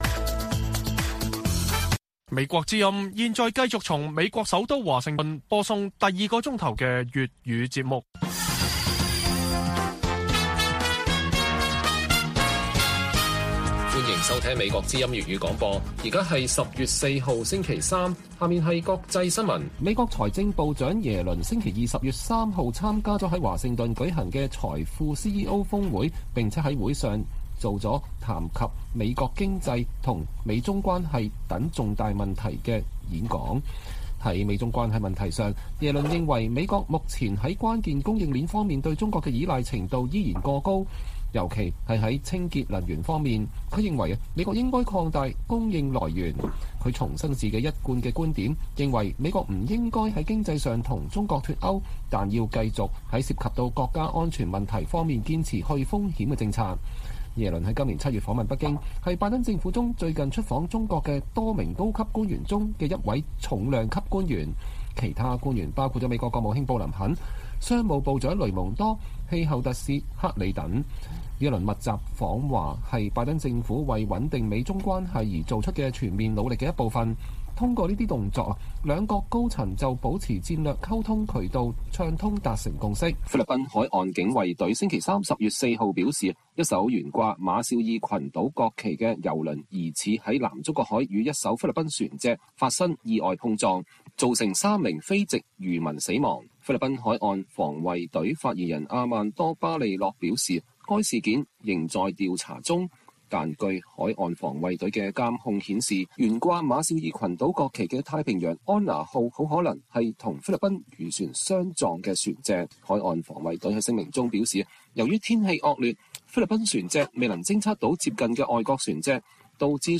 粵語新聞 晚上10-11點: 聯合國專家去信老撾查問中國人權律師盧思位情況